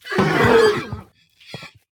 CosmicRageSounds / ogg / general / combat / creatures / horse / he / die1.ogg
die1.ogg